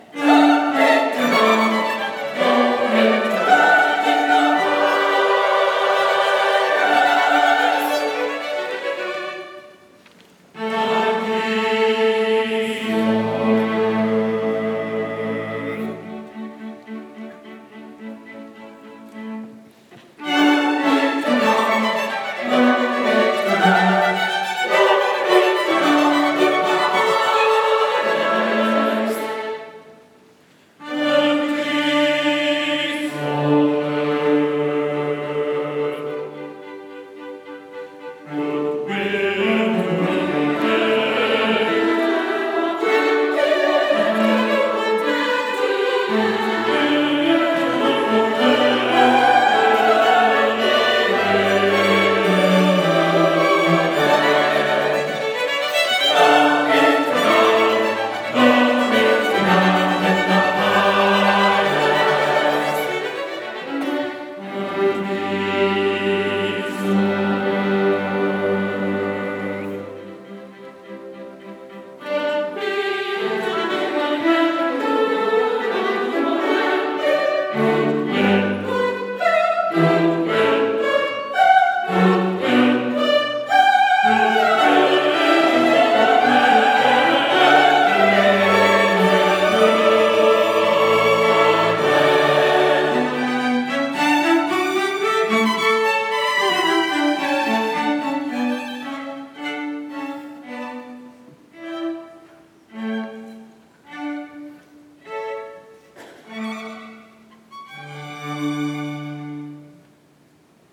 December 13, 2015 Concert